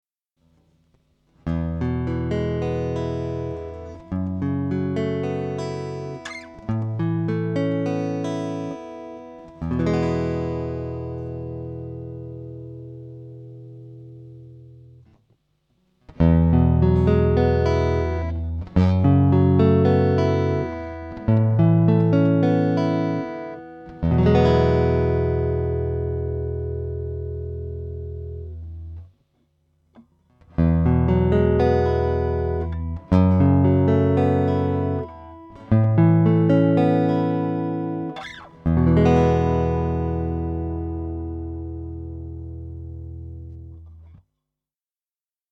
Звукосниматель выдает слабый, но приближенный к натуральному звучанию акустической гитары звук.
Так как это хамбакер, то сигнал выдает он мощный, без помех и шумов, но уже далекий от звука акустической гитары, поэтому его можно смело использовать с усилительным оборудованием и педалями эффектов так же как и следующий звукосниматель.
Звук Sound King выдает похожий на предыдущий звукосниматель, но из-за неуверенного крепления в розетке у меня, например, первая струна оказалась мимо магнита и поэтому звучит тише остальных.
Записал звукосниматели в «линию» и в процессор эффектов.
Seymour duncan, KQ-3, Sound King